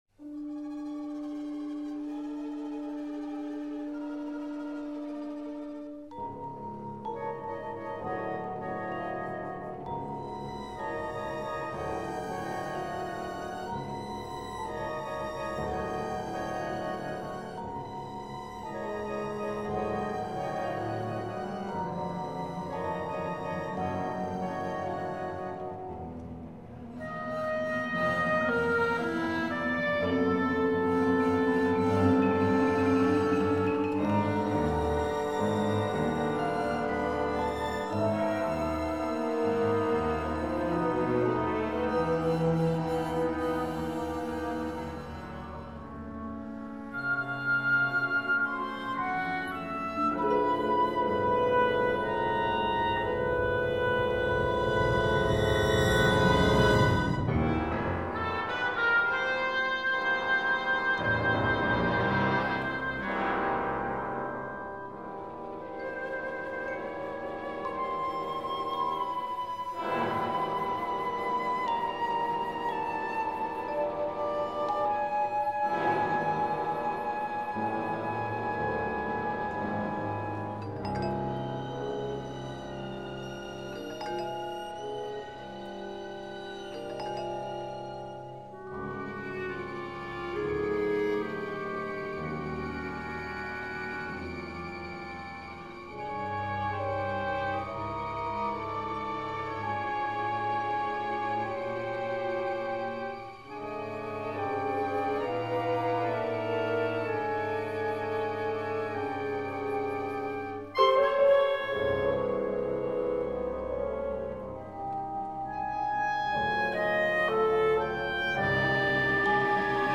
full of suspense, mystery, and thrills.